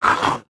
khanat-sounds-sources/_stock/sound_library/animals/monsters/mnstr10.wav at b47298e59bc2d07382d075ea6095eeaaa149284c